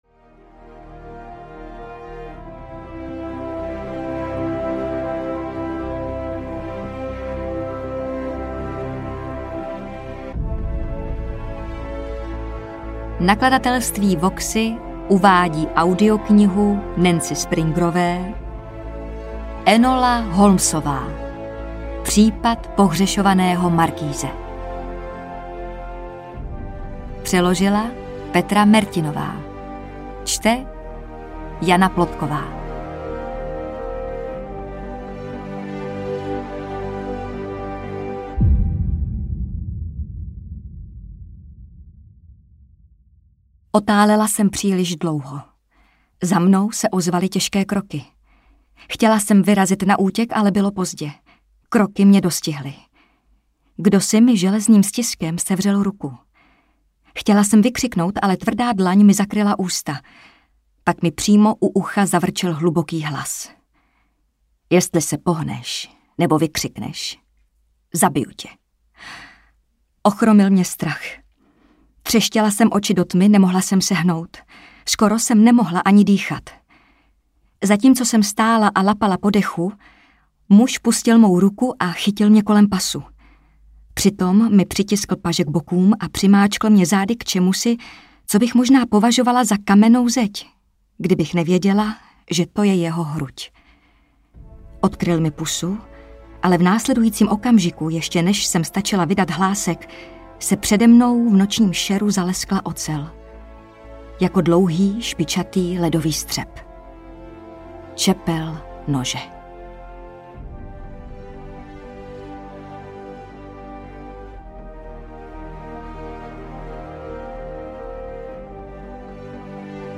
Enola Holmesová - Případ pohřešovaného markýze audiokniha
Ukázka z knihy
• InterpretJana Plodková